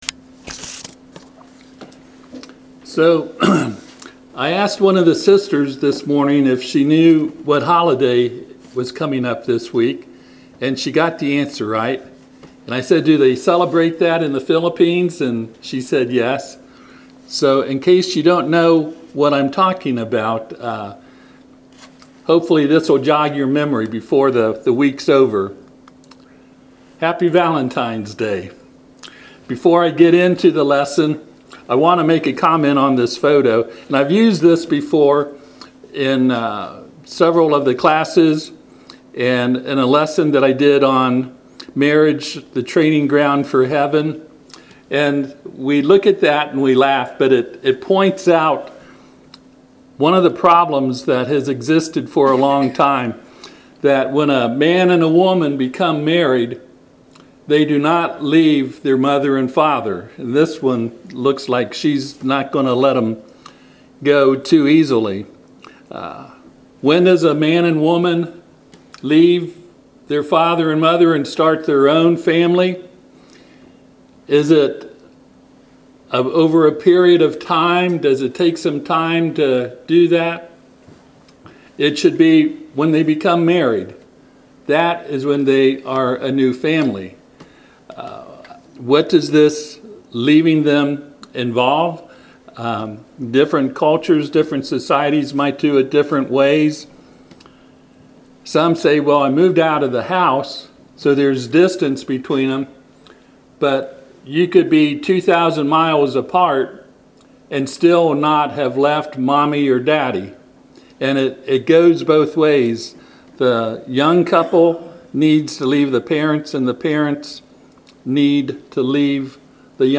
Passage: Ephesians 5:27-33 Service Type: Sunday AM